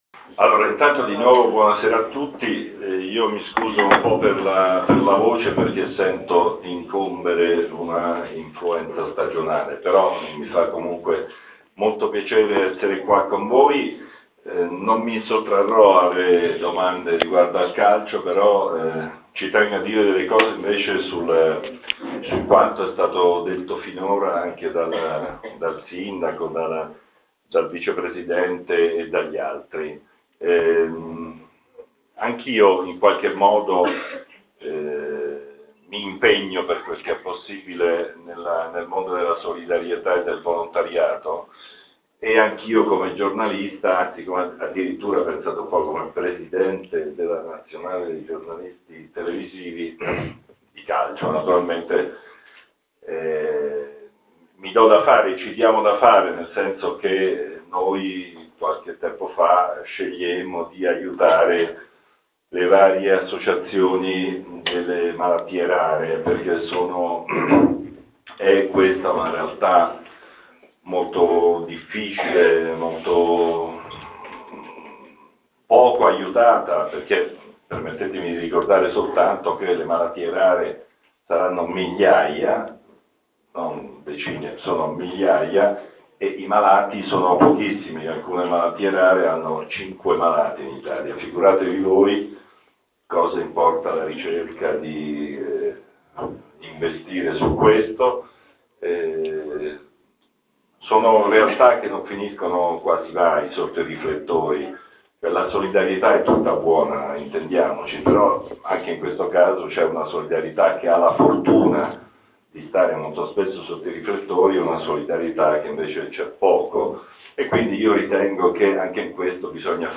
Riascolta l'intervento Lamberto Sposini (Foligno, 18 febbraio 1952) è un giornalista e conduttore televisivo italiano.